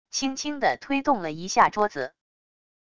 轻轻的推动了一下桌子wav音频